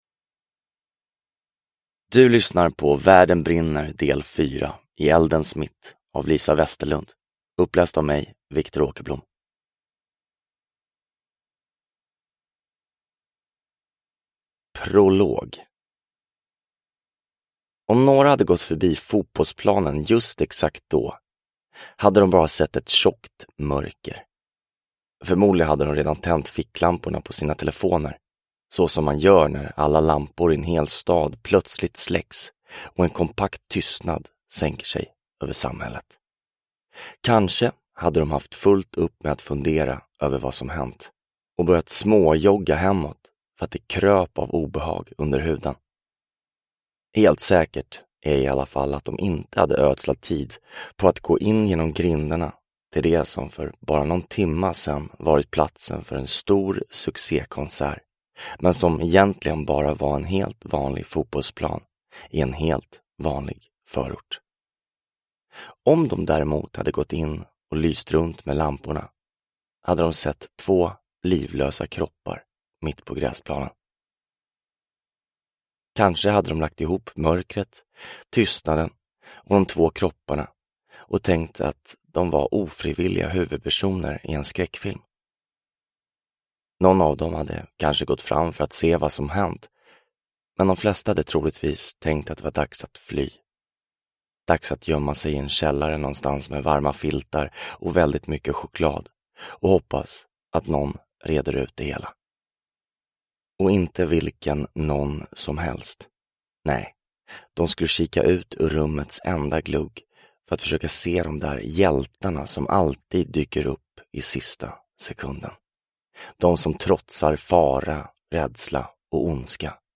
I eldens mitt – Ljudbok – Laddas ner